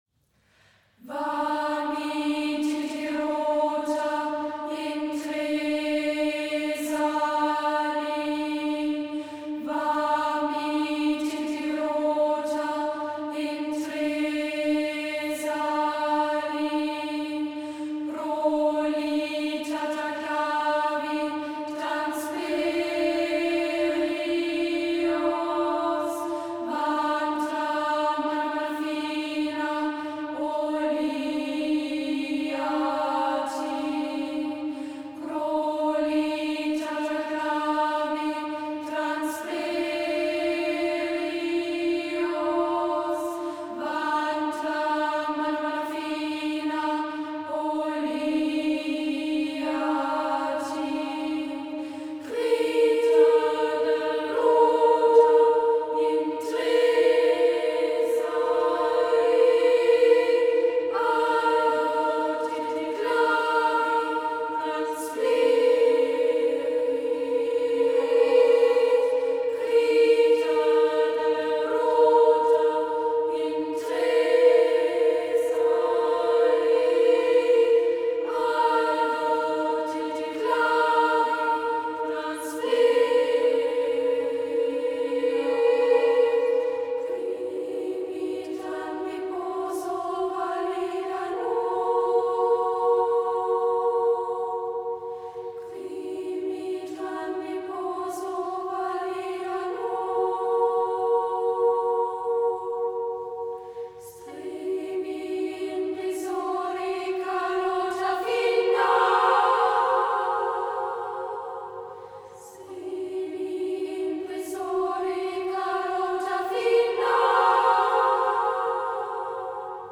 Jennefelt: Virita criosa - Konzertchor Sång
Referenz-Aufnahme